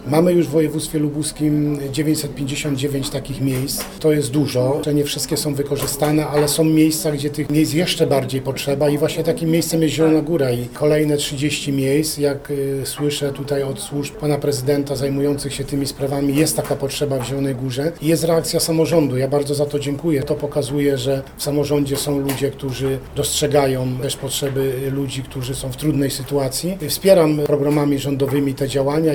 Mówi prezydent Zielonej Góry Janusz Kubicki: